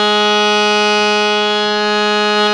52-key10-harm-g#3.wav